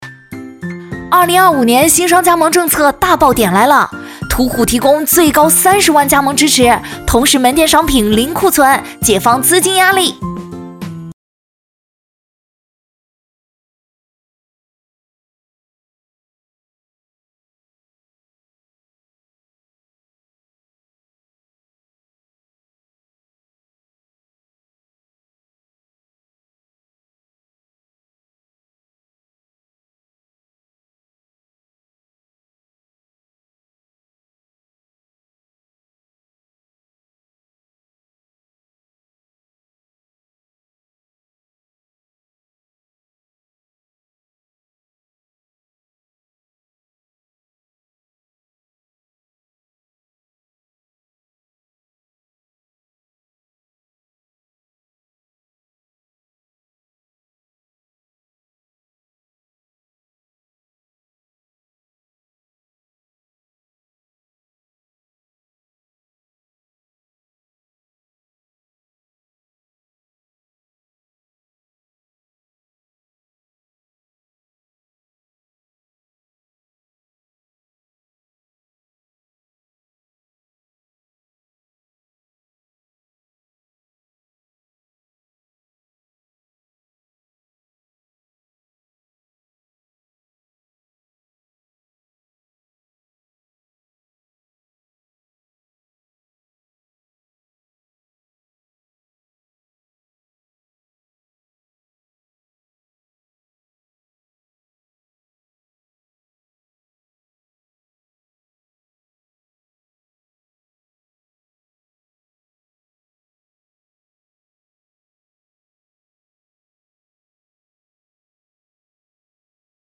大气、温暖、知性、甜美、性感、明亮、低沉 。擅长TVC、纪录片、解说、走心旁白、宣传片、专题等全风格